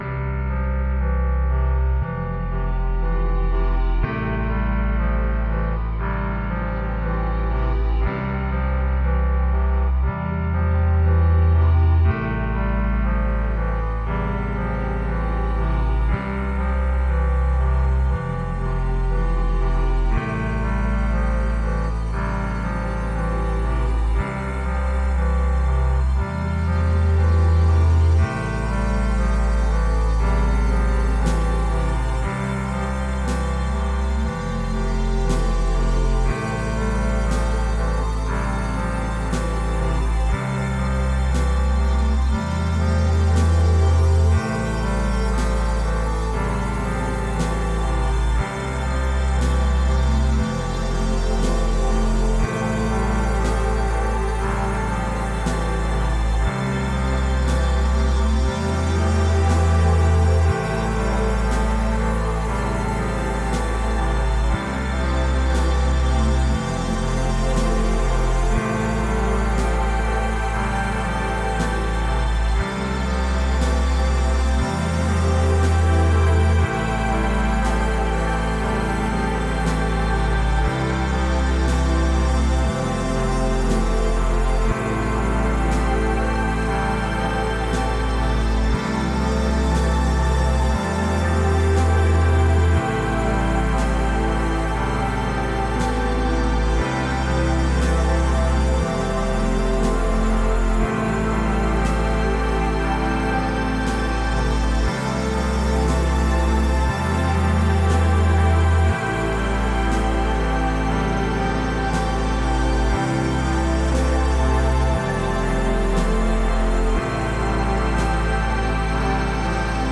Ambient